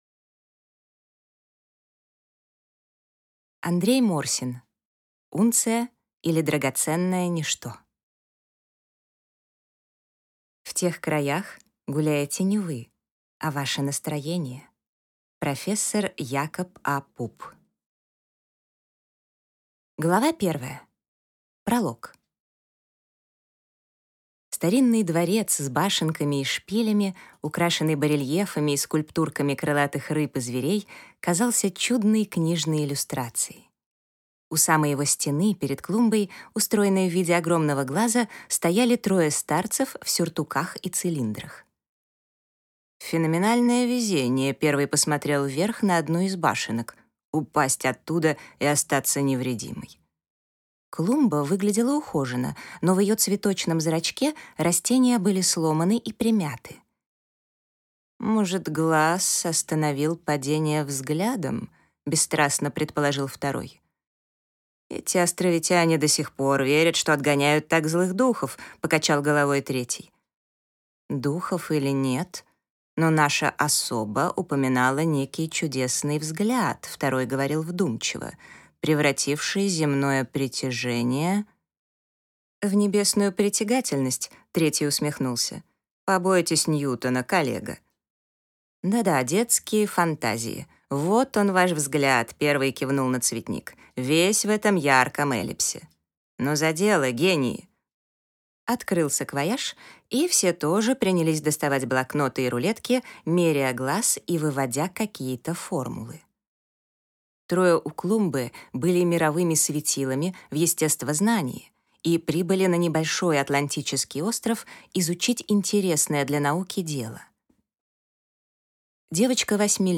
Аудиокнига Унция, или Драгоценное Ничто | Библиотека аудиокниг